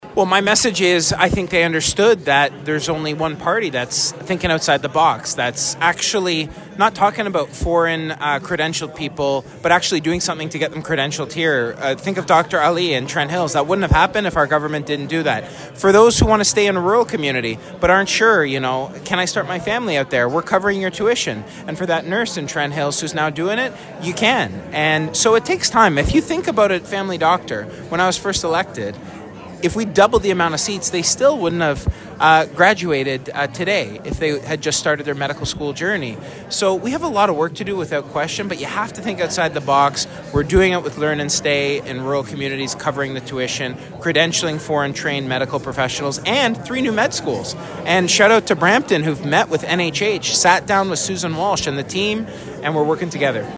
Further on the subject of healthcare, Piccini had this to say.